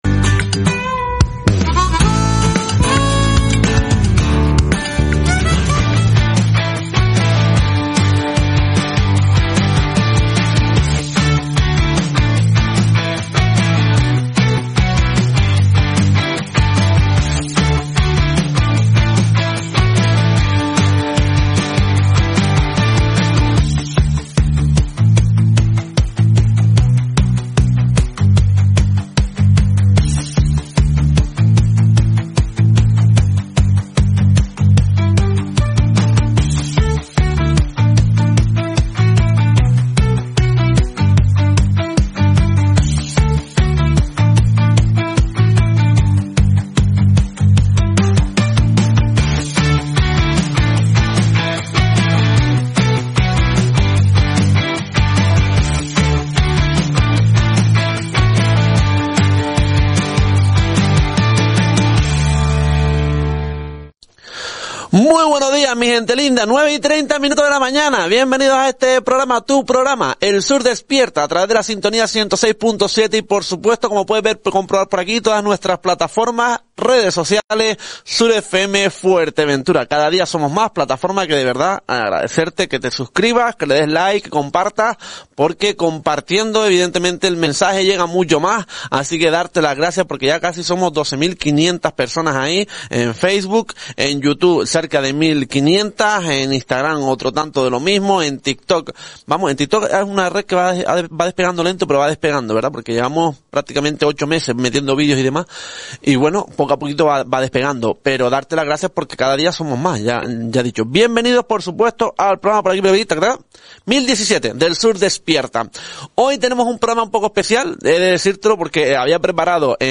De esta manera comenzaba el programa matinal de SurFM